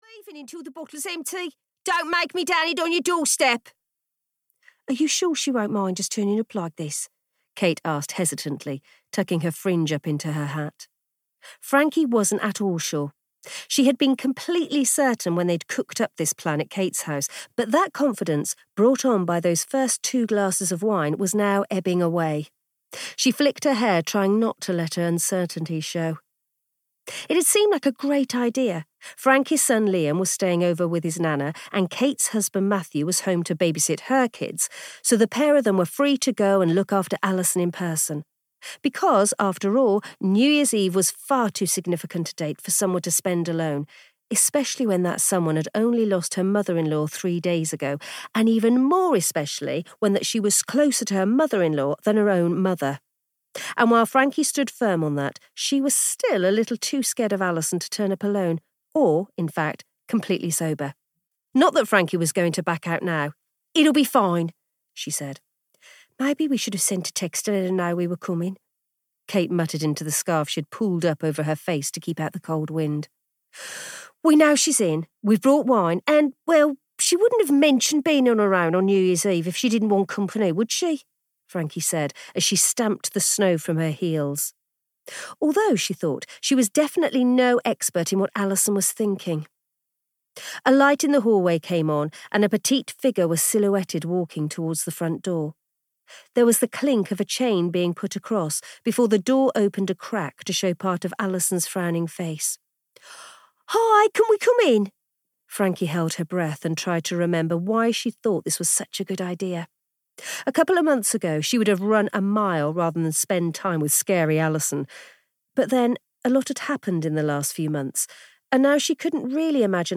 The Happiness Project (EN) audiokniha
Ukázka z knihy